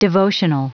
Prononciation du mot devotional en anglais (fichier audio)
Prononciation du mot : devotional